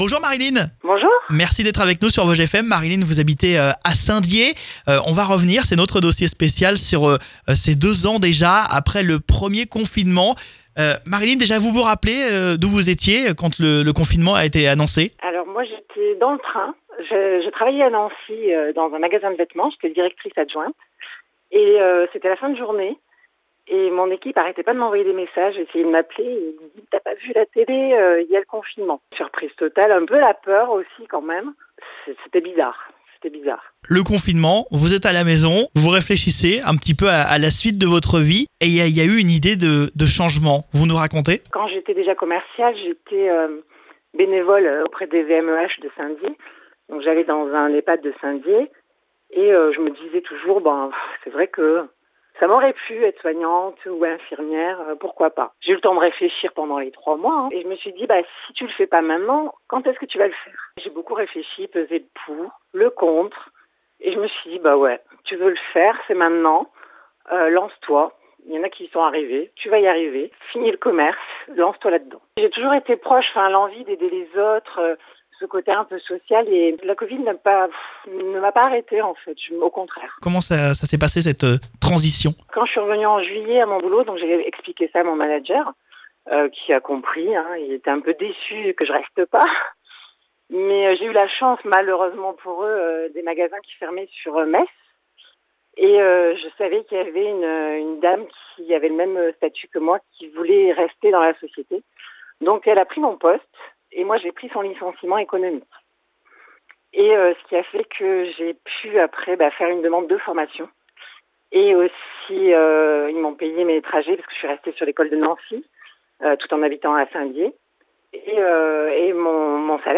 Un témoignage positif qui montre que la crise sanitaire n'a pas été une mauvaise chose pour tous.